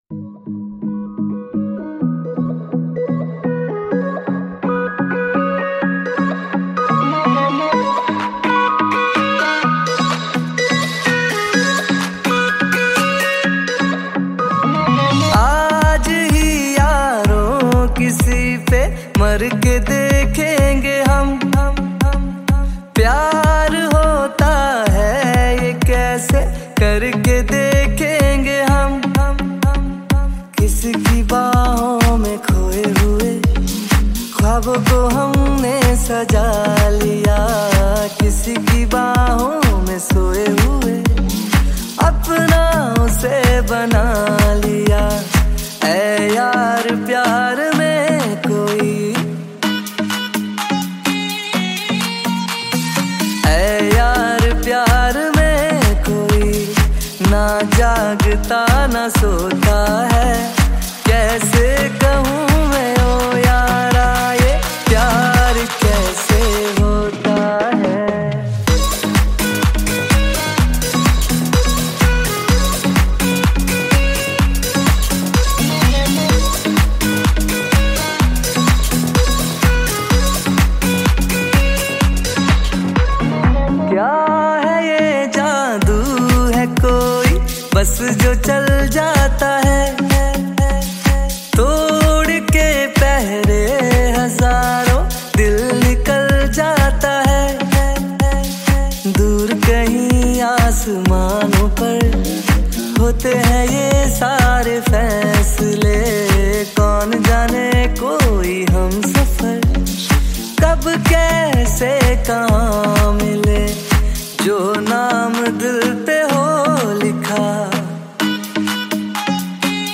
Bollywood Cover Songs